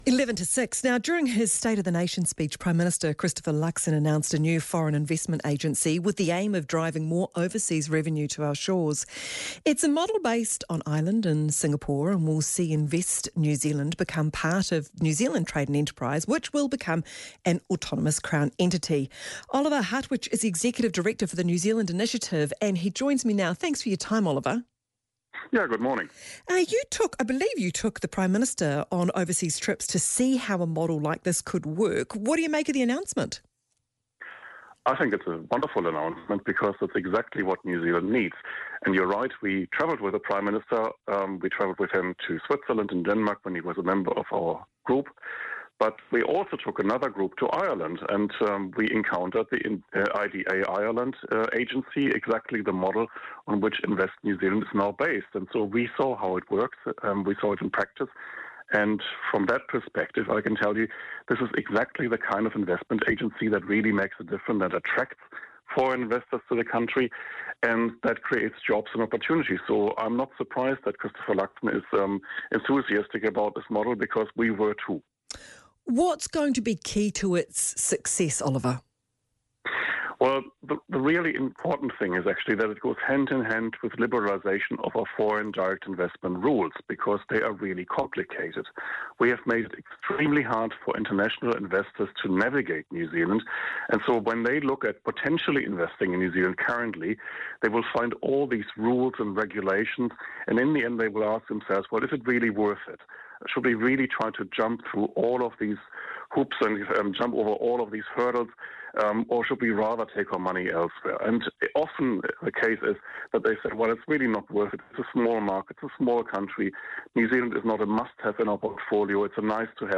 was interviewed on Newstalk ZB about Prime Minister Christopher Luxon's announcement of Invest New Zealand, a new foreign investment agency modeled after Ireland's successful IDA Ireland model.